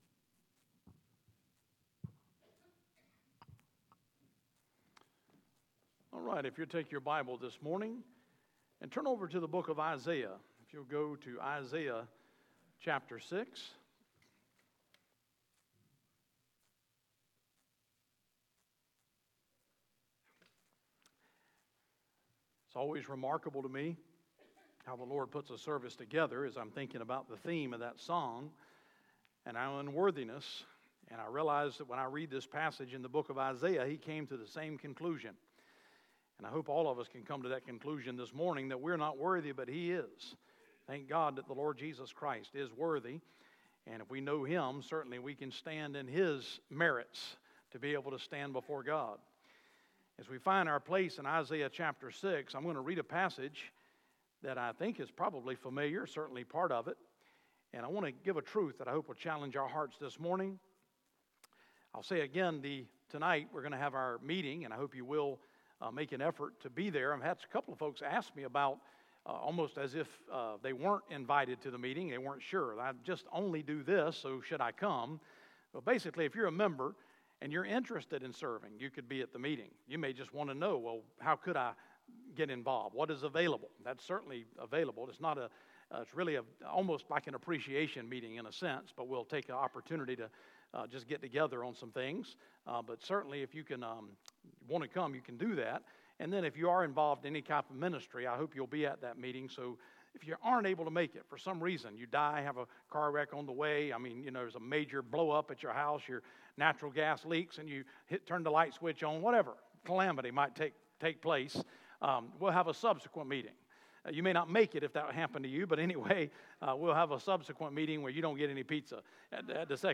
Sermons | Tri-City Baptist Church